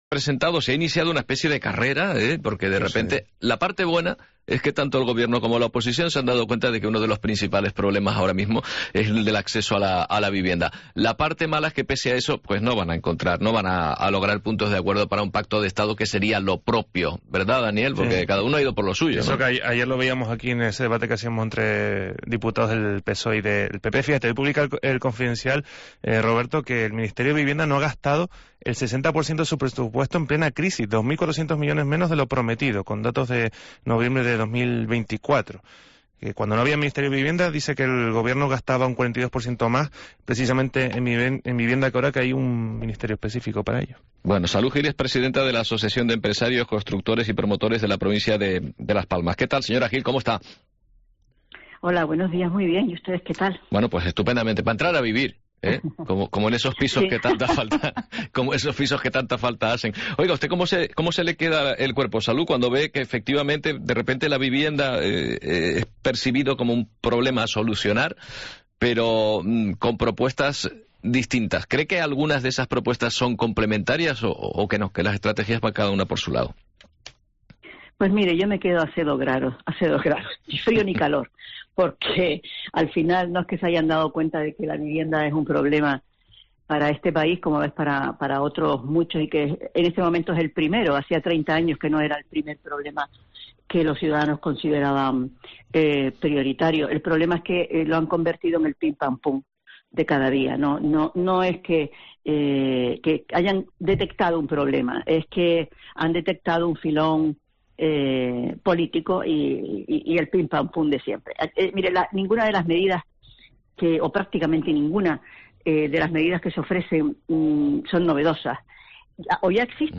Entrevista sobre vivienda